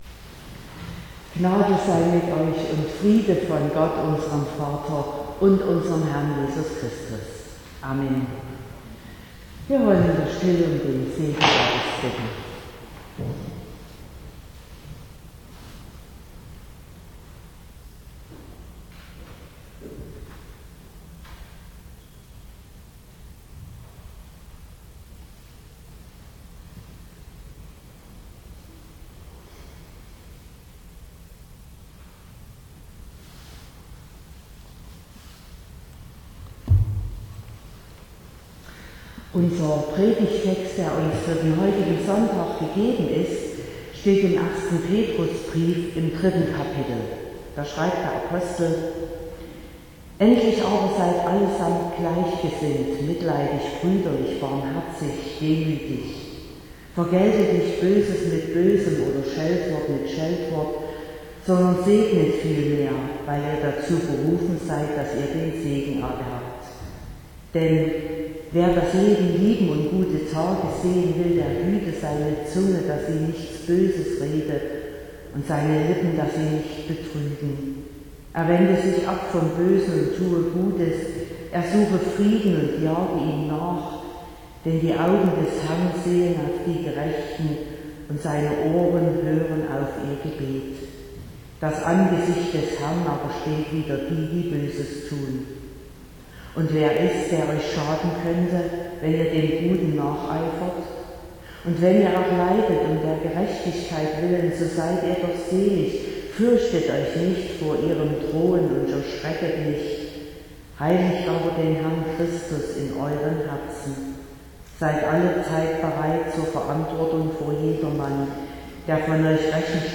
02.07.2023 – Gottesdienst
Predigt (Audio): 2023-07-02_Was_unterscheidet_uns.mp3 (27,6 MB)